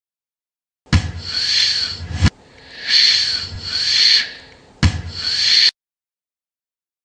boom
60586-boom.mp3